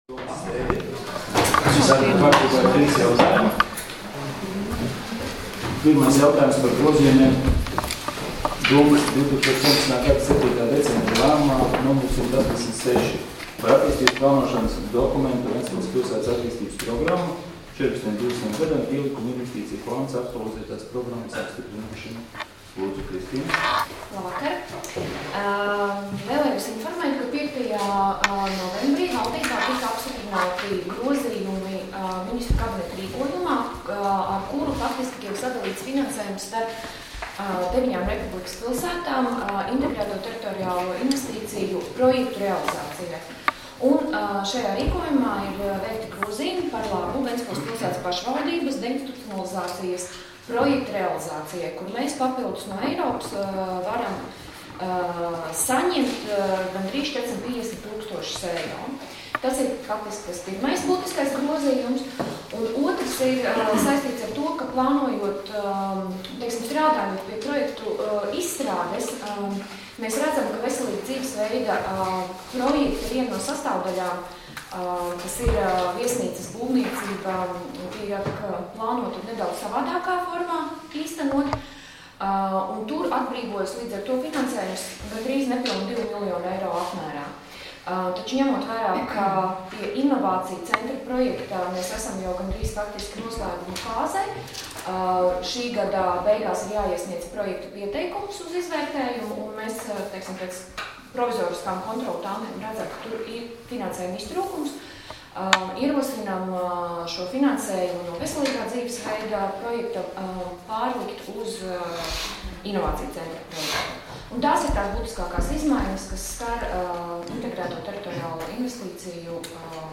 Domes sēdes 13.11.2018. audioieraksts